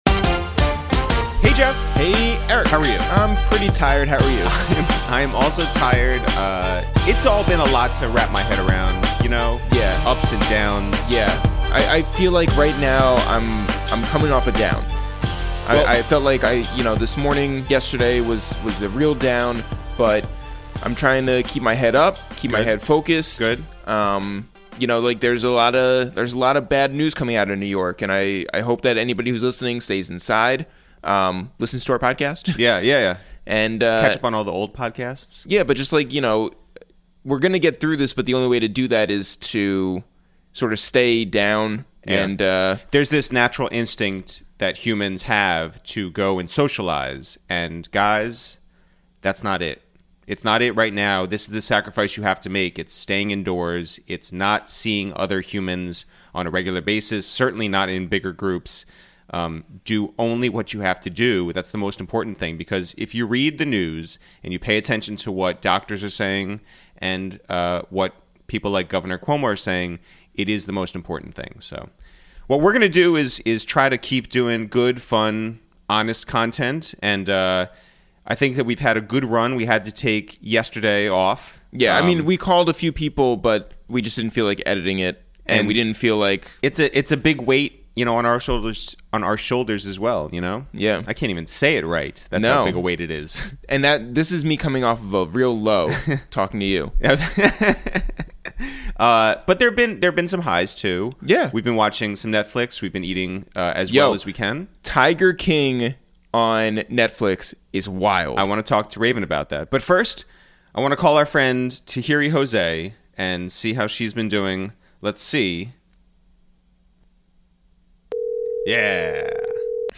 Today on Episode 7 of Quarantine Radio, we make calls from our Upper West Side apartment to check in on TV personality & entrepreneur Tahiry Jose